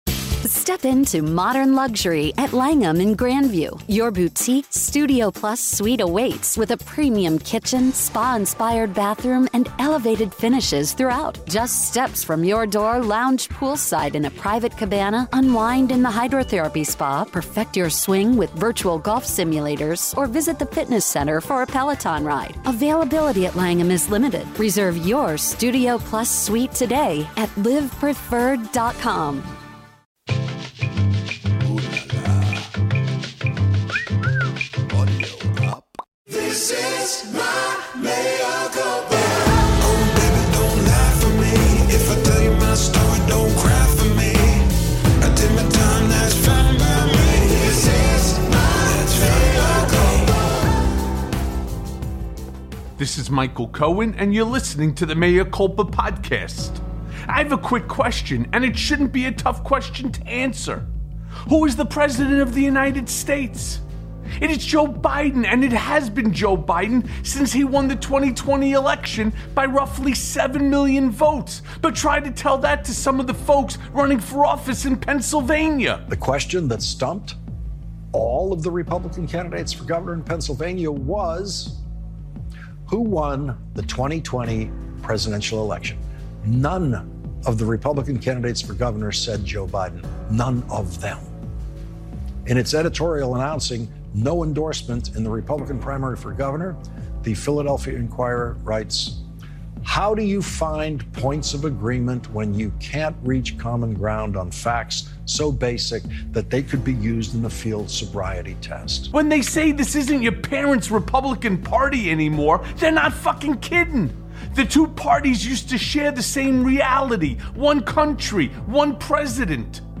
Breaking!!! Psycho Mini-Trumps Dominate in GOP Primaries + A Conversation with Richard Painter
Richard Painter, former White House Ethics Lawyer and now a candidate for U.S. House of Representatives joins Mea Culpa and shares insight on the race from the inside.